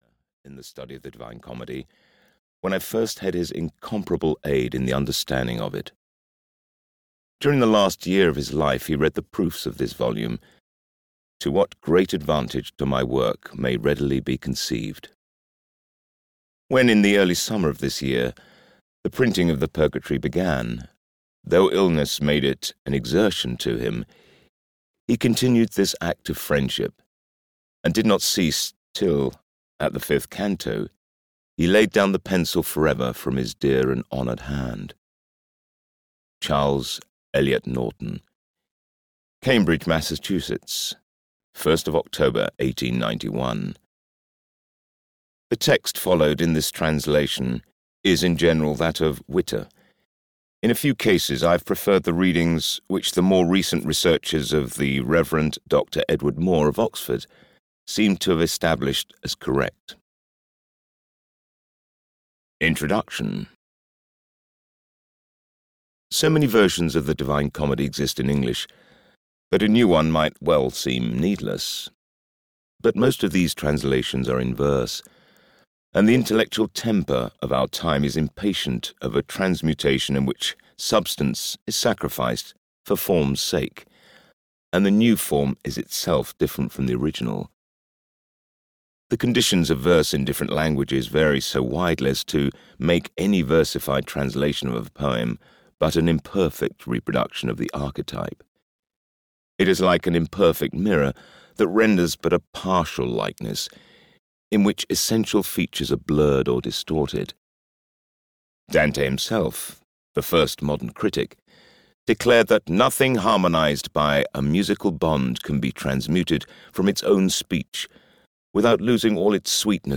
The Divine Comedy 1: Hell (EN) audiokniha
Ukázka z knihy